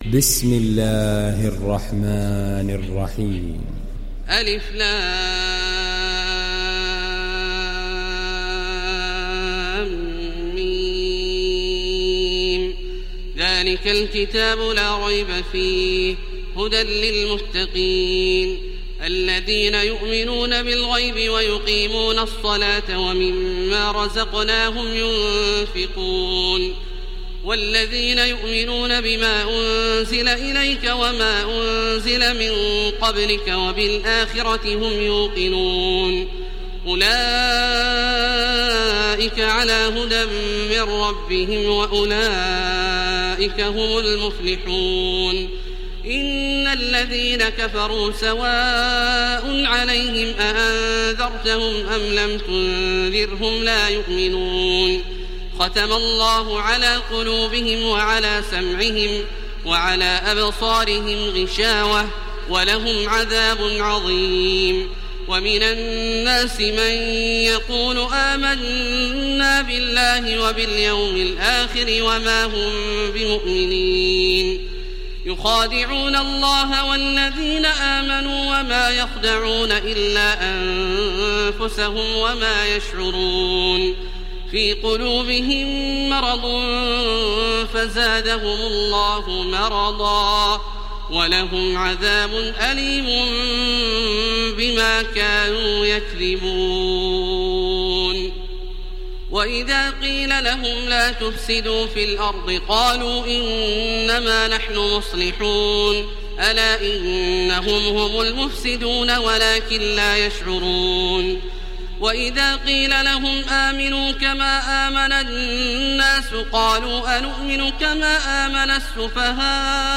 Download Surat Al Baqara Taraweeh Makkah 1430